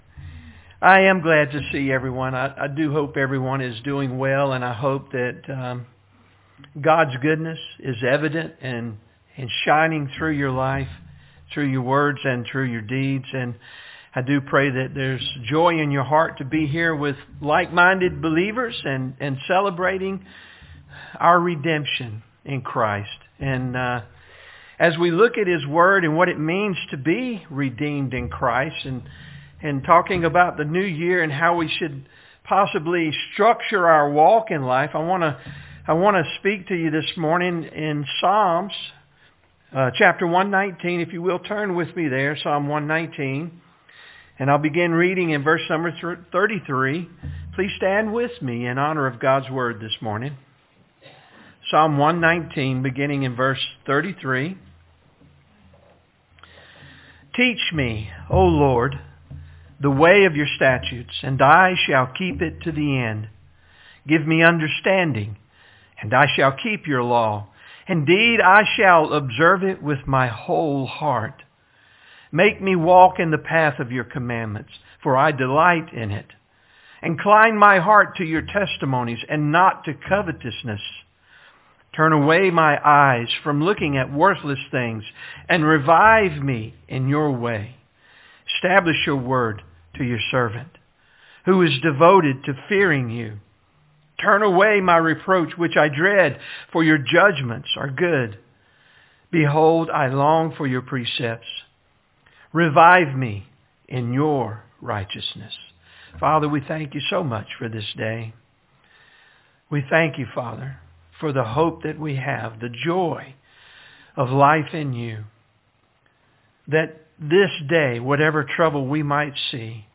All Sermons Who Will We Be?